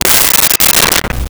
Glass Bottle Break 05
Glass Bottle Break 05.wav